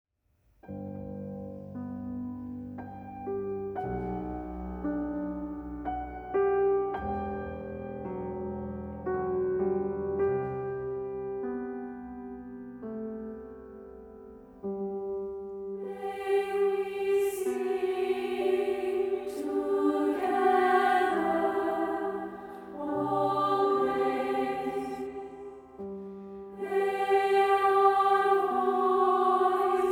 • Classical